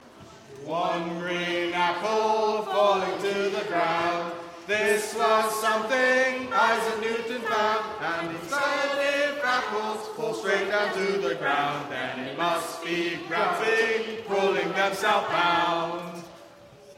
Singing History Concert 2016: One Green Apple 1